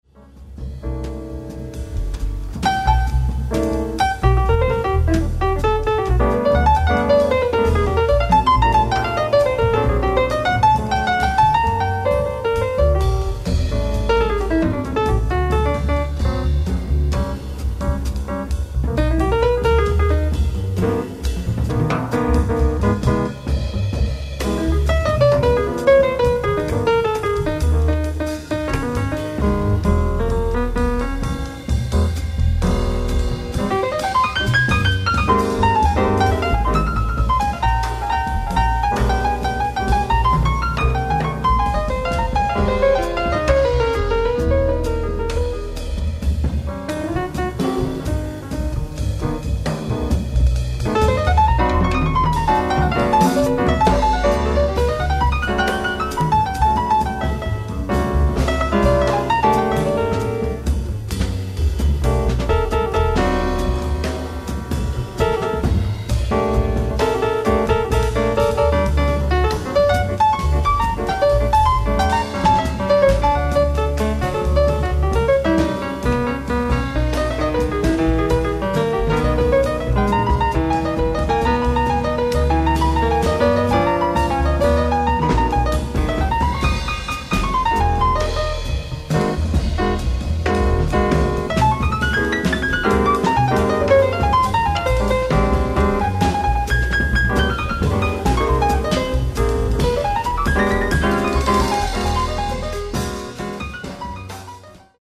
ライブ・アット・ジャズ・ア・ジュアン、アンティーブス、フランス 07/22/1999
全曲放送音源からの完全版！！
※試聴用に実際より音質を落としています。